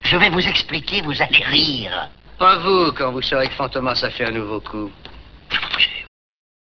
Extraits sonores du film